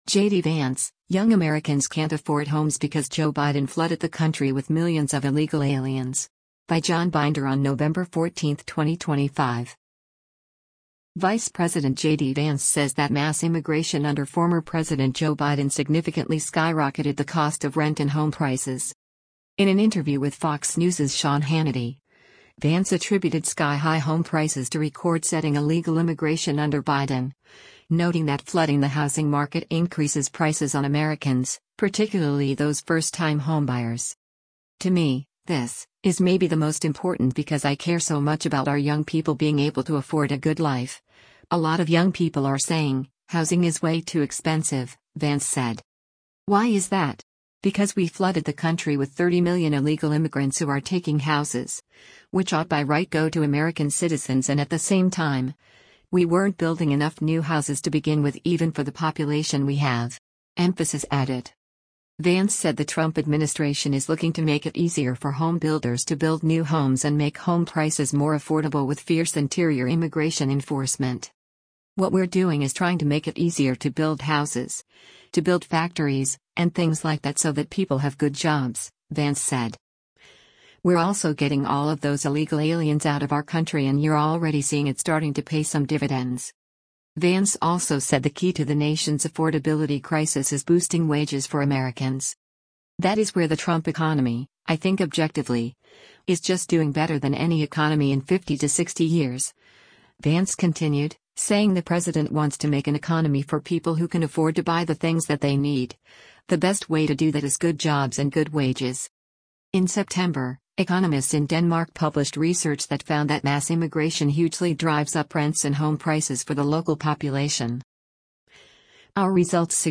In an interview with Fox News’s Sean Hannity, Vance attributed sky-high home prices to record-setting illegal immigration under Biden — noting that flooding the housing market increases prices on Americans, particularly those first-time home-buyers.